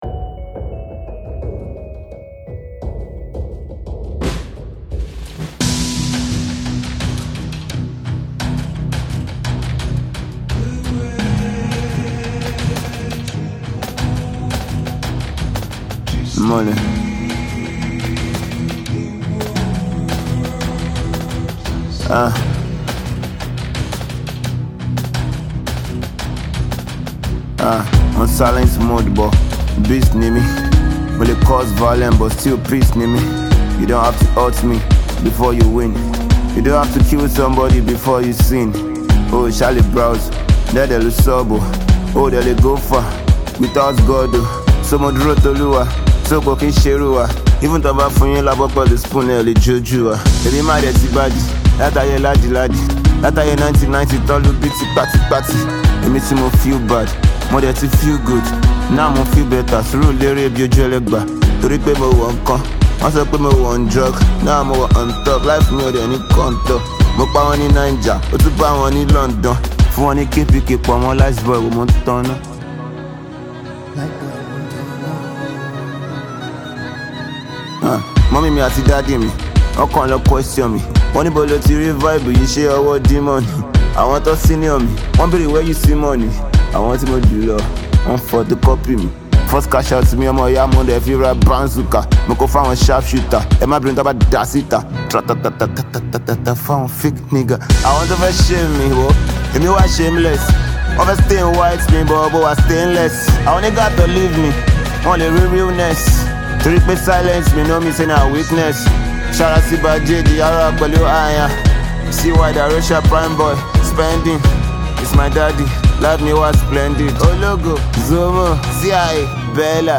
street pop